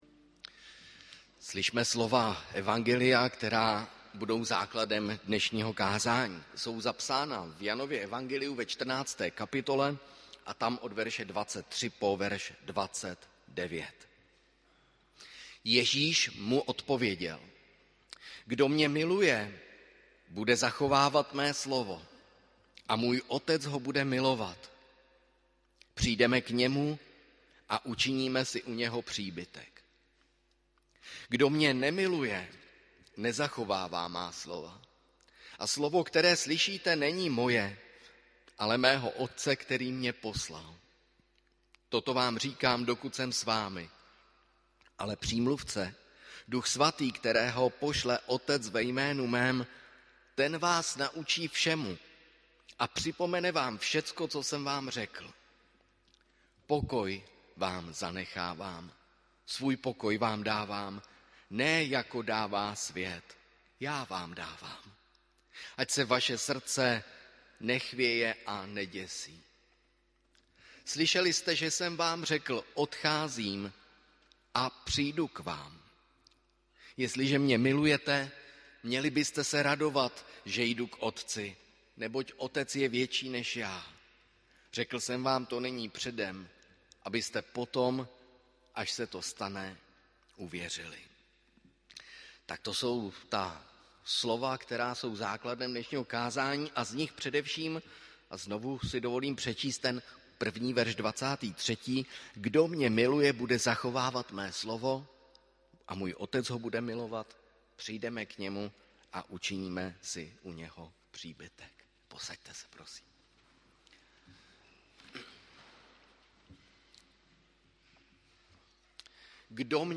Pátá neděle po Velikonocích – Rogate – 22. května 2022 AD
audio kázání